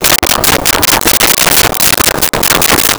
Chickens In A Barn 06
Chickens in a Barn 06.wav